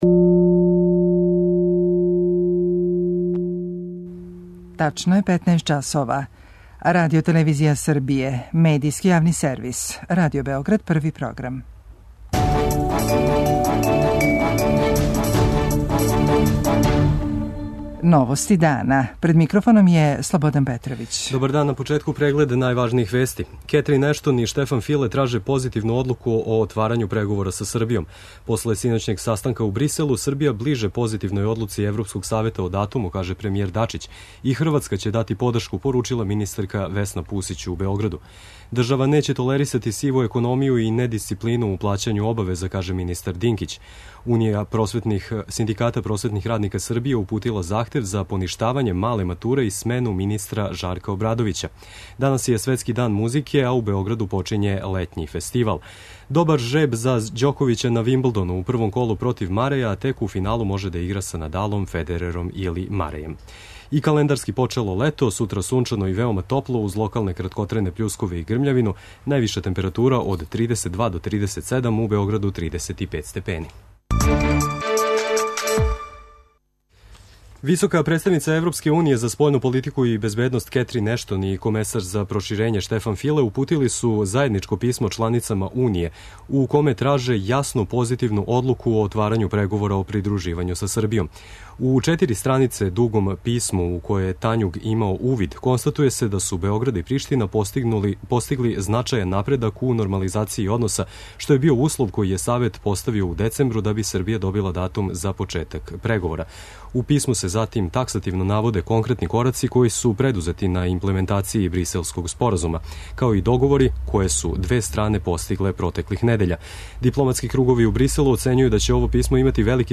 Дорис Пак, која ускоро завршава свој четврти шестогодишњи мандат у ЕП, у интервјуу за Радио Београд 1, оценила је да је дошао тренутак да се Србији одреди датум за отпочињање преговора о придружењу.